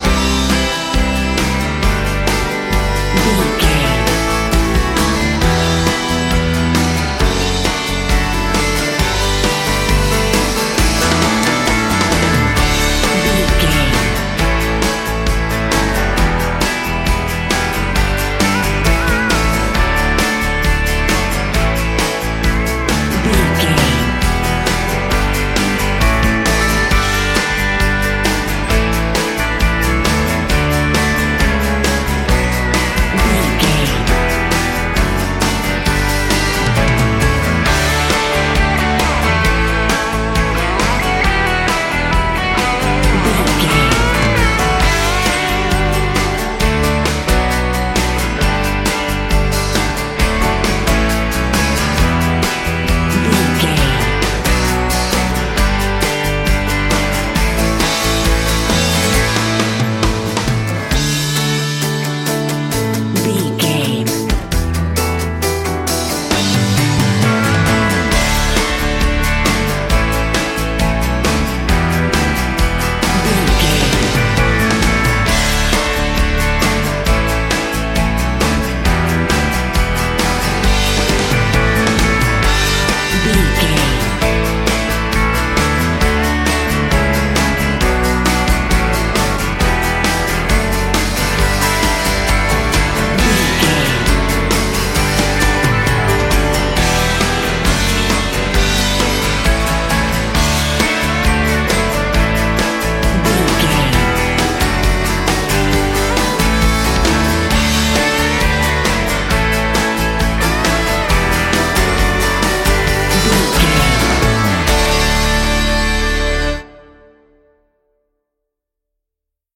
Ionian/Major
electric guitar
drums
bass guitar
violin